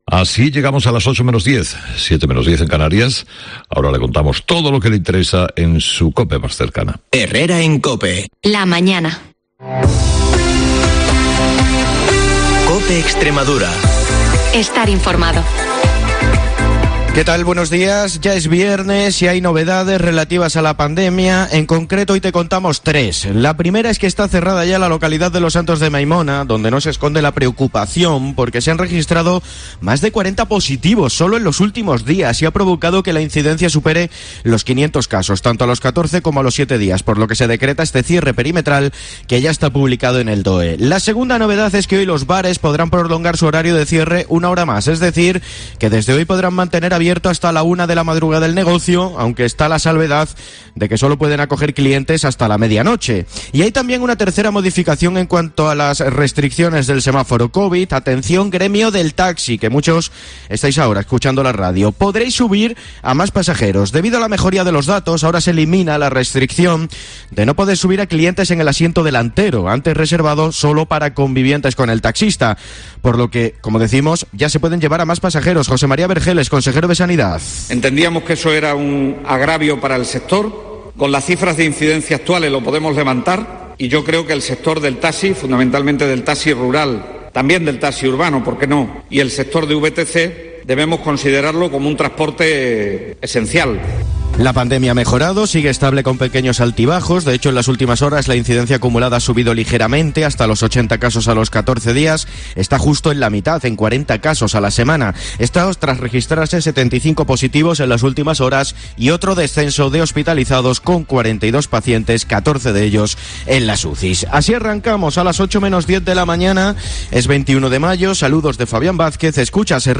el informativo más escuchado de Extremadura